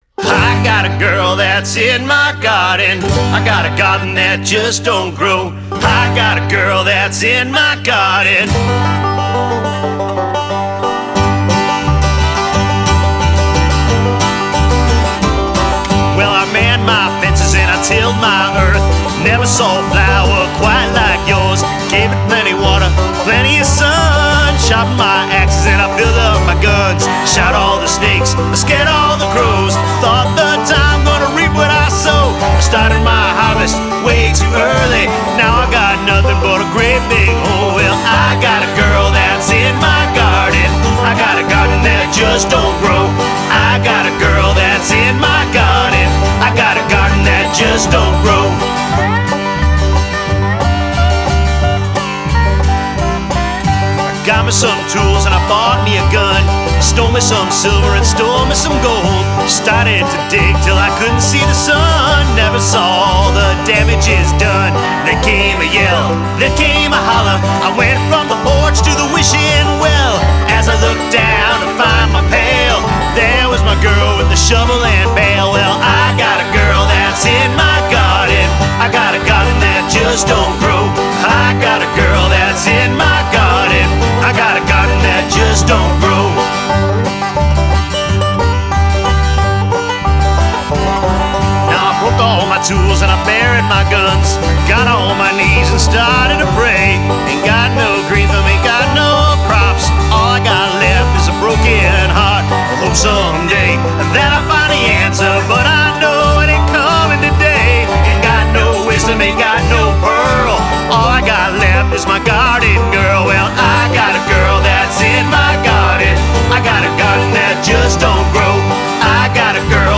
Genre: Americana.